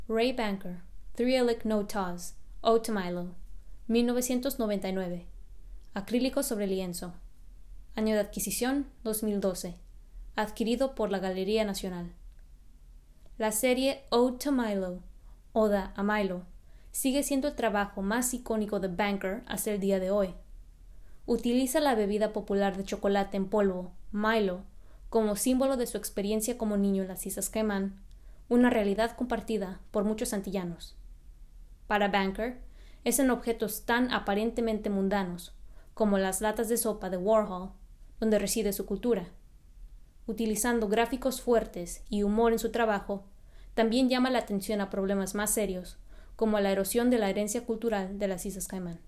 (English) Wray Banker - 3 a Lick, No Taws (Ode to Milo series) Voiceover (Español) Wray Banker - 3 a Lick, No Taws (Ode to Milo series) Narración